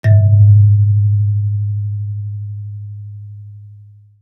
kalimba_bass-G#1-mf.wav